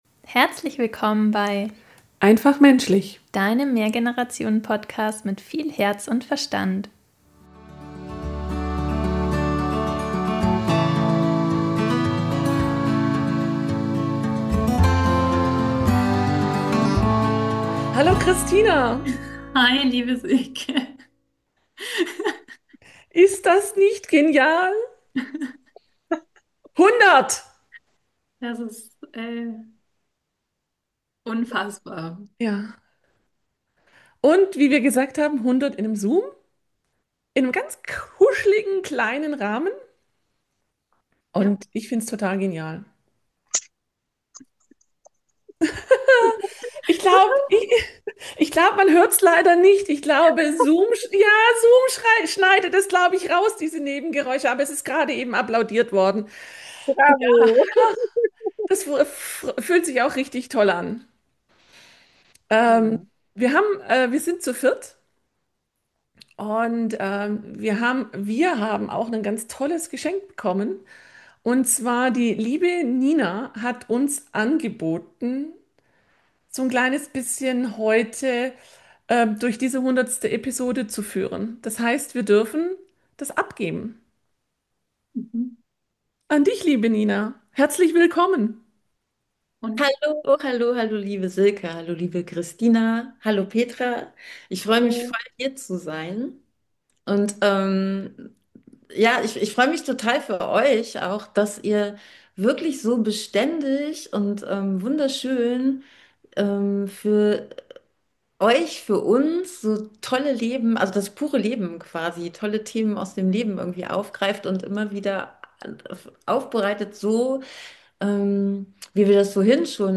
#100: Celebration - Wir gehen live! ~ Einfach menschlich Podcast